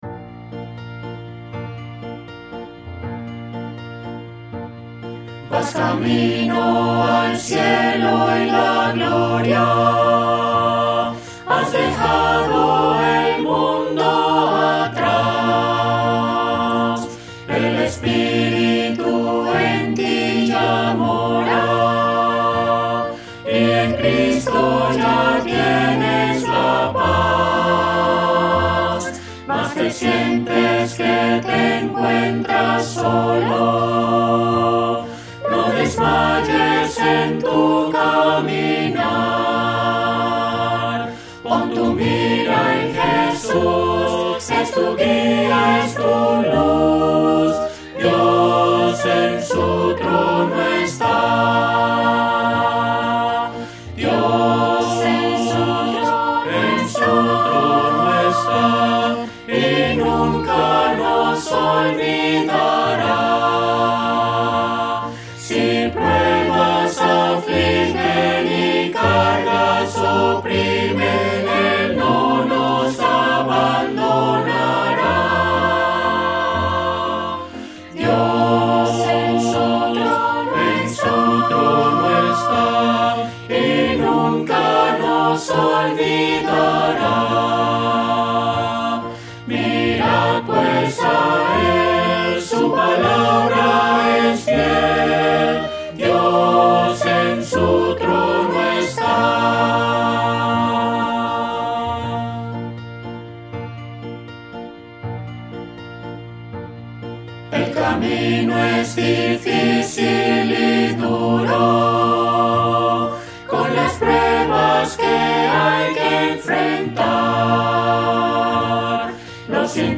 Cantado (Descargar audio)